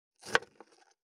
528台所,野菜切る,咀嚼音,ナイフ,調理音,まな板の上,料理,
効果音